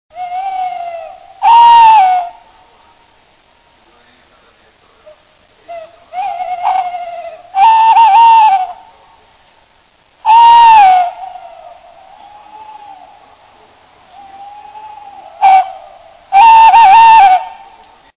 Allocco
Canto del maschio (+)
Strix-aluco-1.mp3